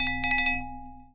chime_bell_05.wav